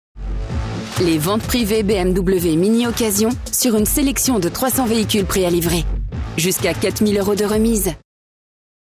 Automotive
Mezzo-Soprano